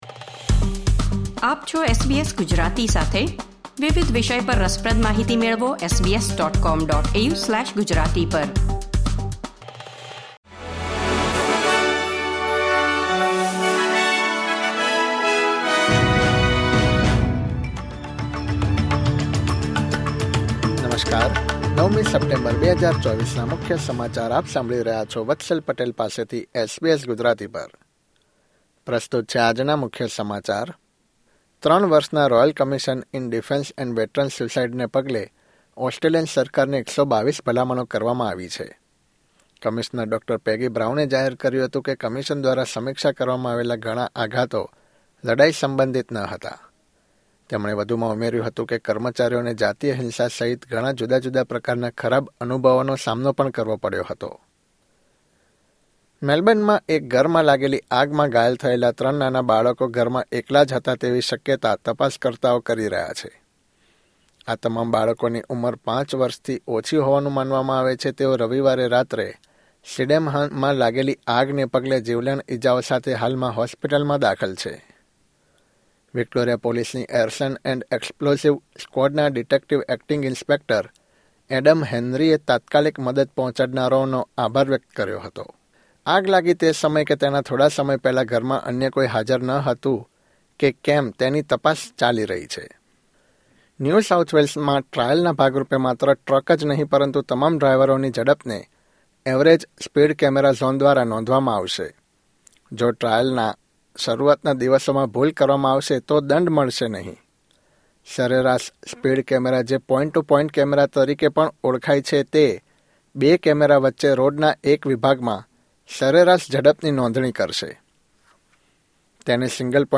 SBS Gujarati News Bulletin 9 September 2024